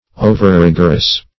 Overrigorous \O"ver*rig"or*ous\, a.